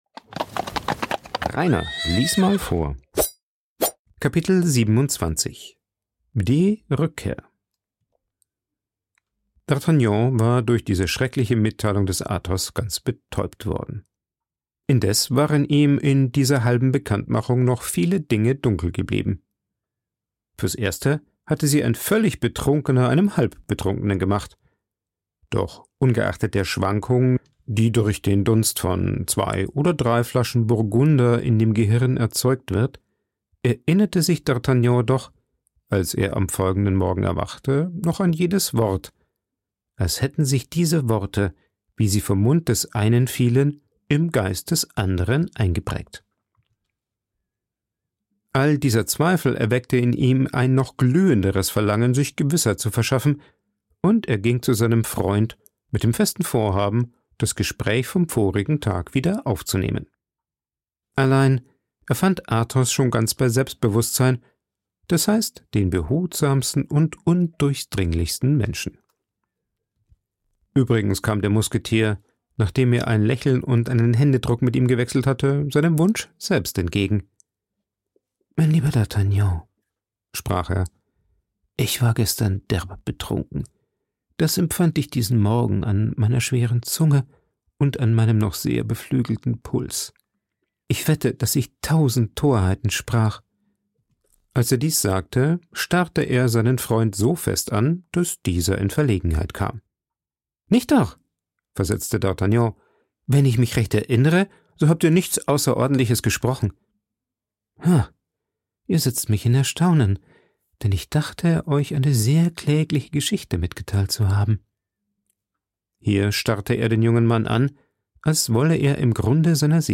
Vorgelesen
aufgenommen und bearbeitet im Coworking Space Rayaworx, Santanyí, Mallorca.